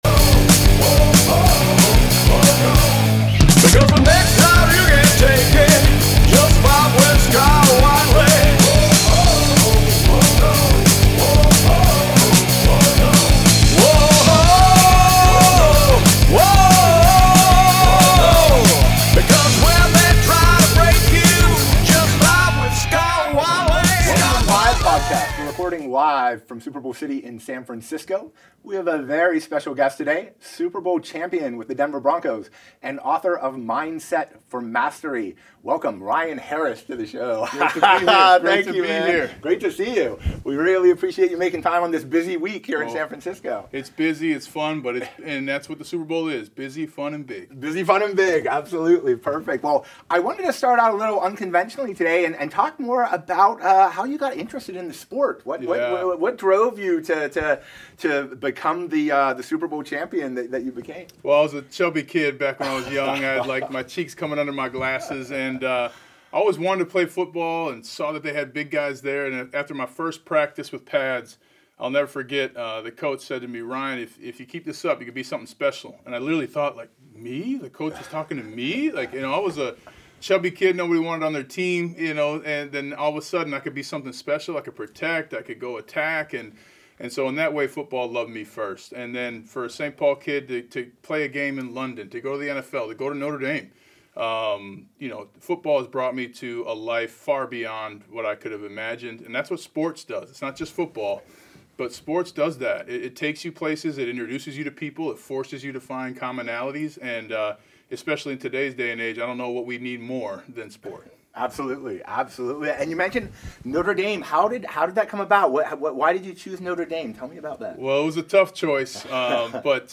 a powerful conversation about what it really takes to win the big one and what comes after the confetti falls. From hoisting the Lombardi Trophy to navigating life after football, Ryan shares hard-earned lessons on leadership, mental resilience, and building cultures that don’t just succeed but sustain.